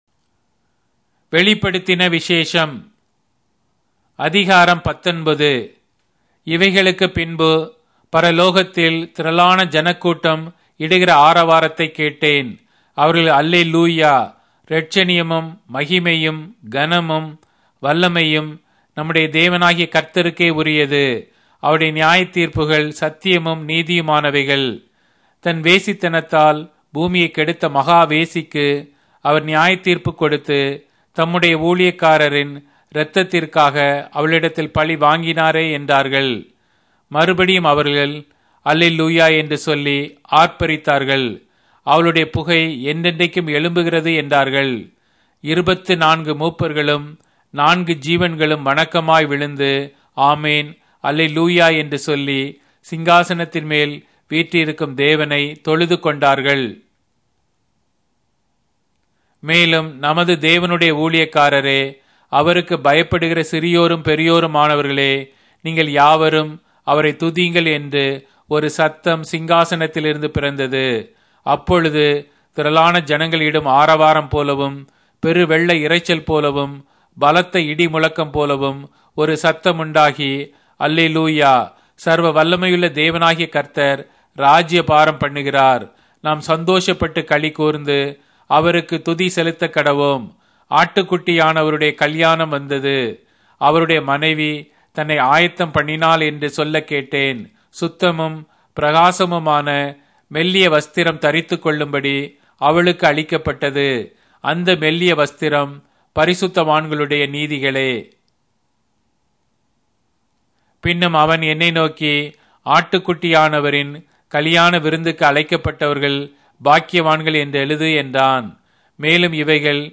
Tamil Audio Bible - Revelation 9 in Tov bible version